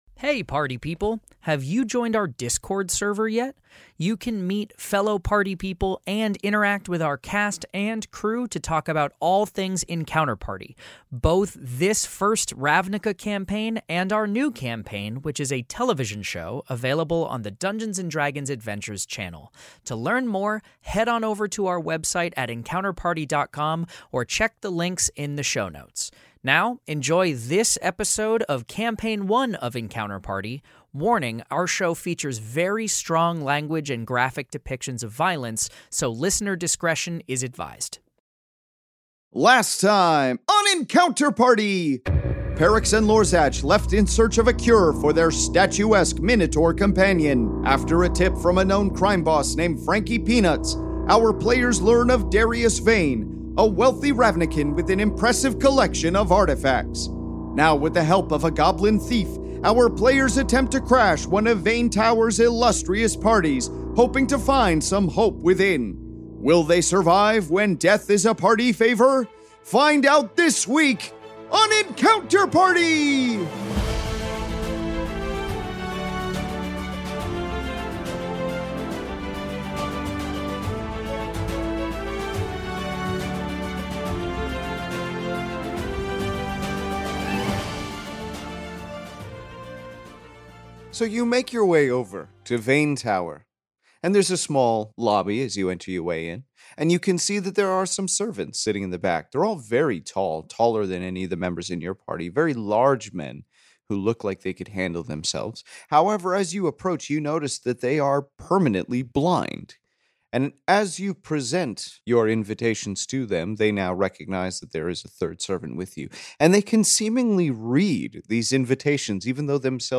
Step inside the Magic: the Gathering world of Ravnica in this Fantasy Mystery Audio Adventure governed by the rules of Dungeons & Dragons